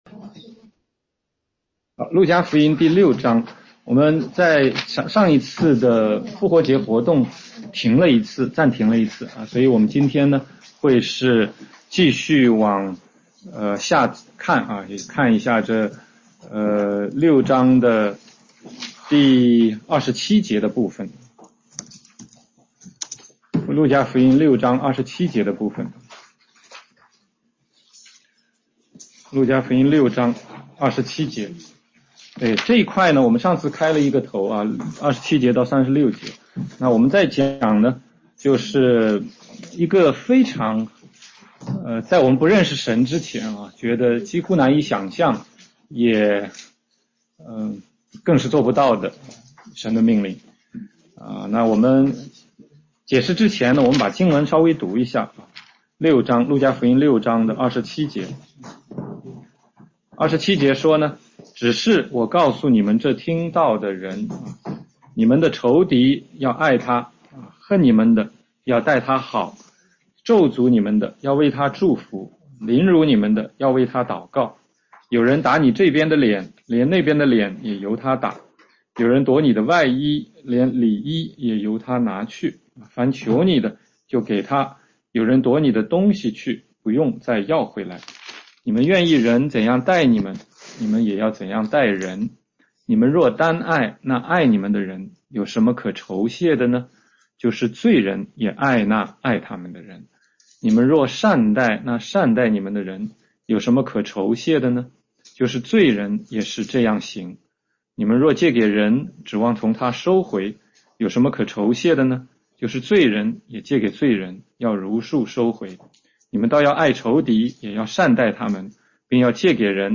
16街讲道录音 - 路加福音6章27-38节：爱仇敌和不可论断人
全中文查经